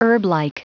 Prononciation du mot herblike en anglais (fichier audio)
Prononciation du mot : herblike